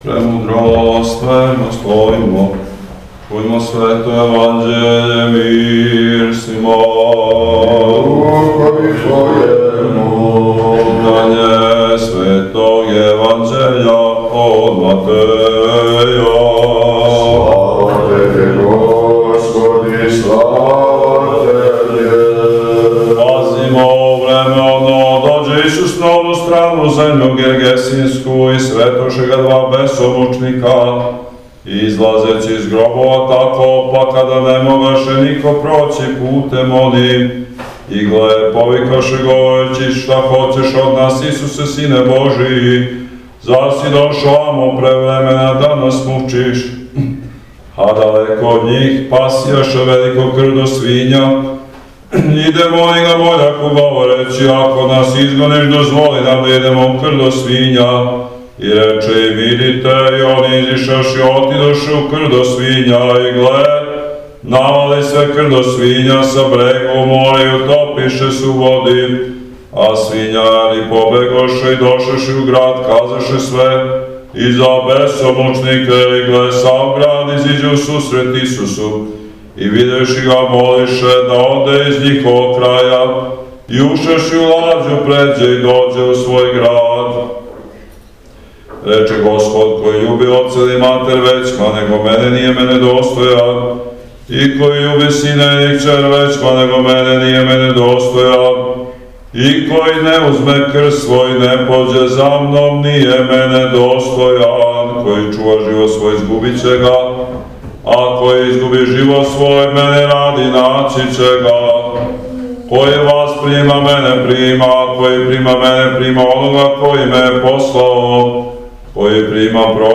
На дан св. Прокопија, у недељу 21. јула 2019. године, у манастиру св. Врача Козме и Дамјана у Зочишту, као и увек сабрало се мноштво благочестивих душа.